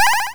reward.wav